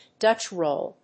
アクセントDútch róll